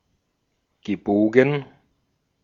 Ääntäminen
Ääntäminen Tuntematon aksentti: IPA: /ɡəˈboːɡŋ̩/ IPA: /ɡəˈboːɡən/ Haettu sana löytyi näillä lähdekielillä: saksa Käännös 1. curvo {m} Gebogen on sanan biegen partisiipin perfekti.